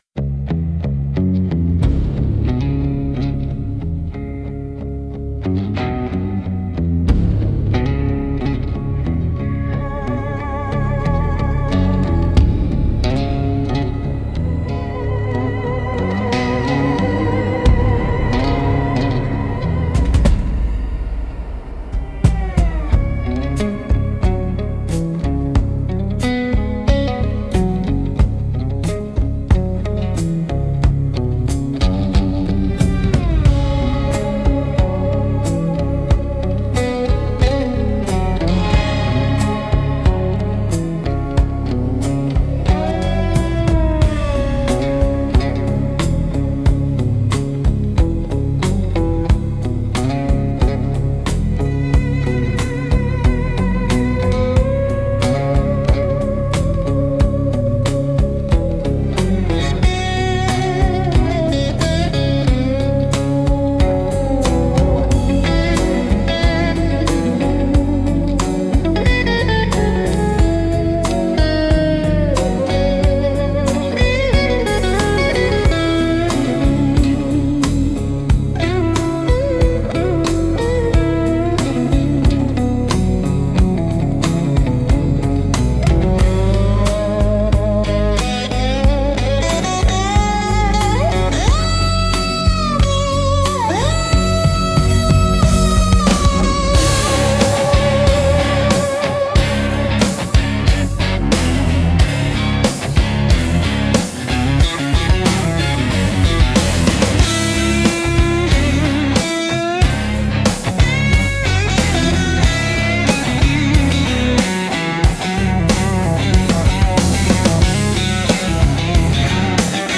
Instrumental - Heartland Tremolo 3.12